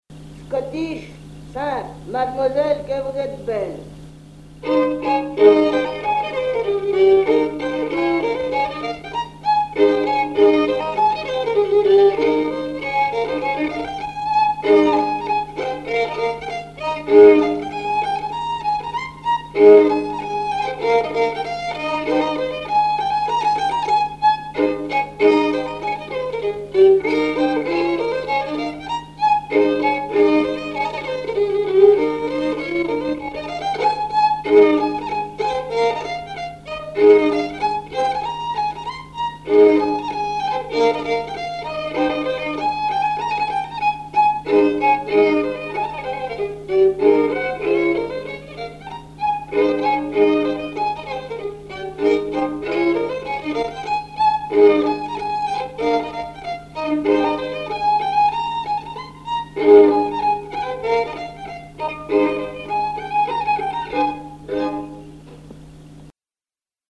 danse : scottich trois pas
Pièce musicale inédite